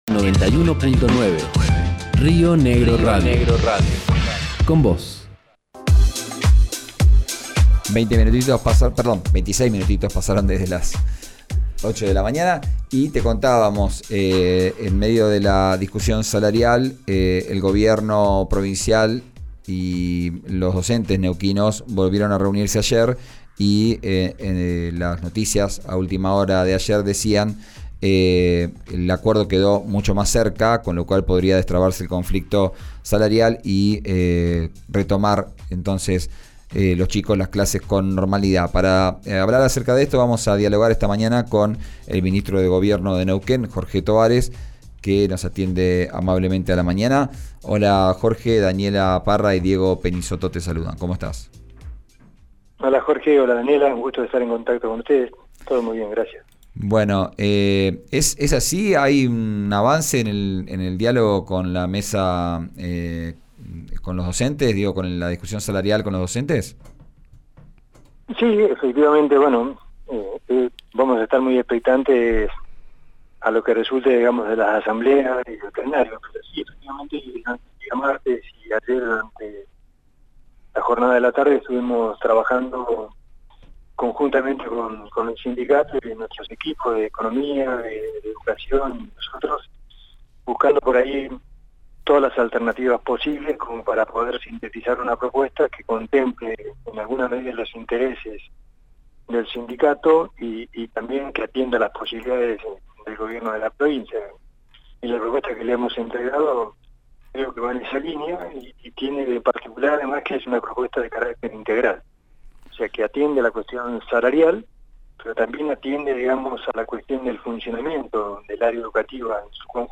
Escuchá al ministro de Gobierno de Neuquén, Jorge Tobares, por RÍO NEGRO RADIO: